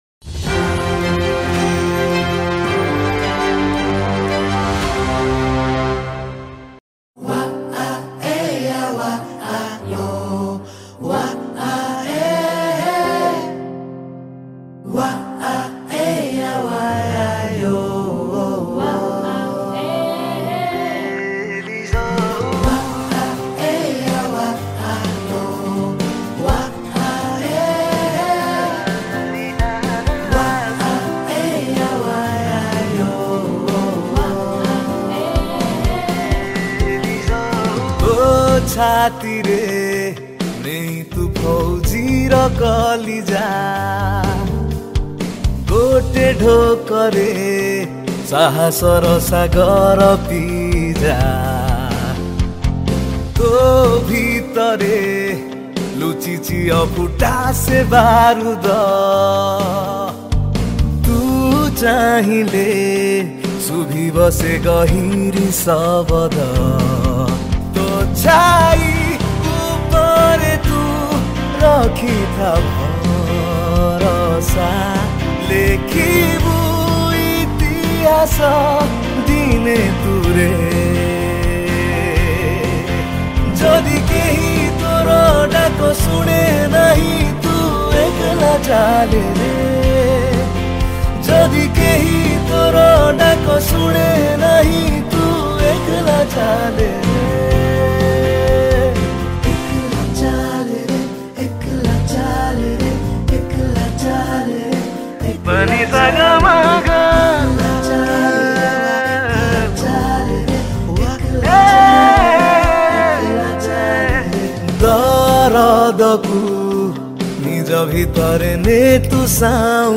Guitar
Backing Vocals